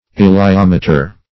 Search Result for " elaiometer" : The Collaborative International Dictionary of English v.0.48: Elaiometer \E`lai*om"e*ter\, n. [Gr.